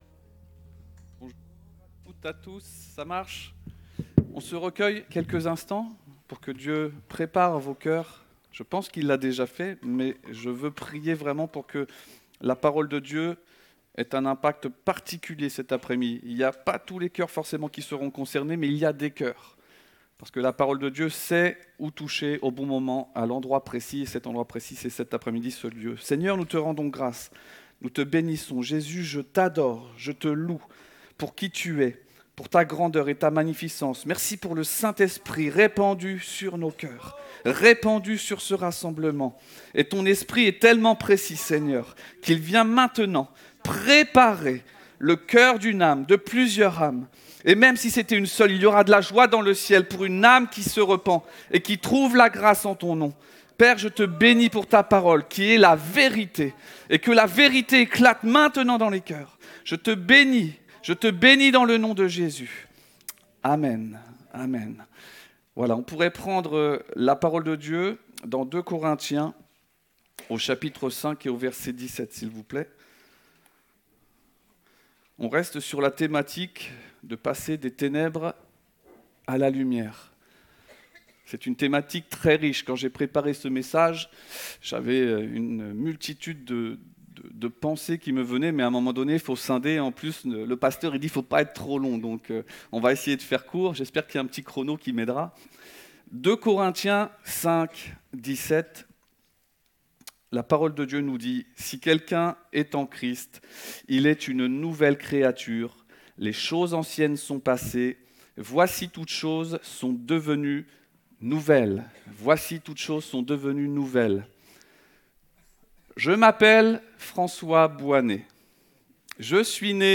Découvrez en replay vidéo le message apporté à l'Eglise Ciel Ouvert